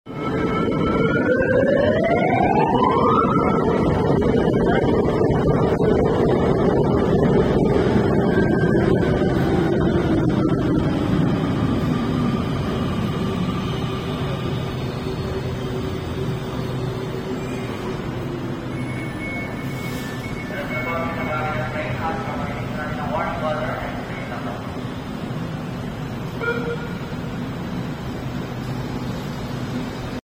LRT-1 4th Generation (LRTA Class 13000) Train Arriving @ Fernando Poe Jr. Station.